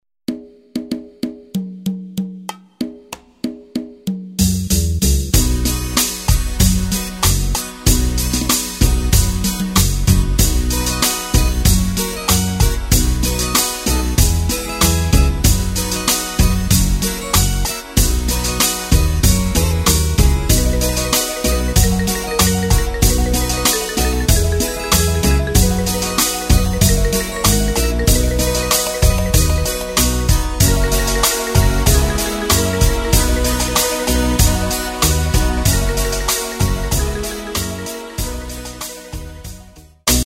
Takt:          4/4
Tempo:         95.00
Tonart:            B
Schlager aus dem Jahr 1965!